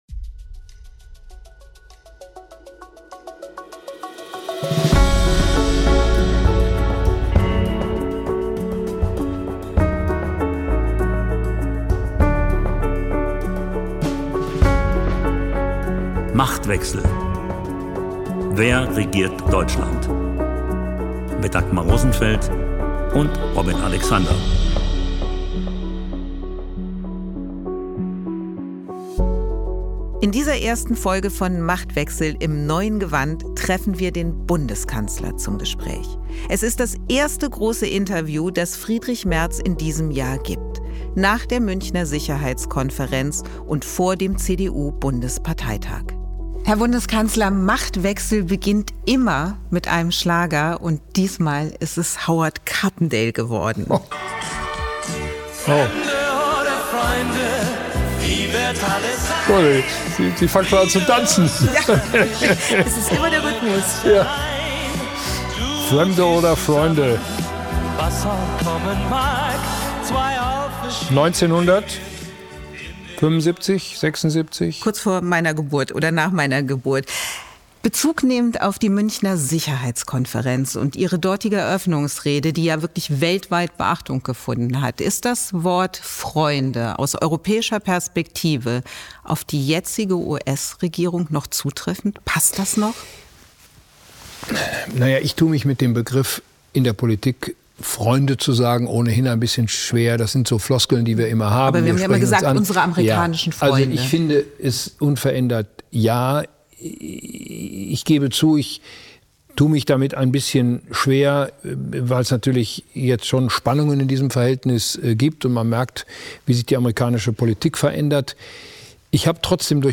Machtwechsel: Der Kanzler im Interview